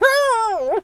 dog_hurt_whimper_howl_04.wav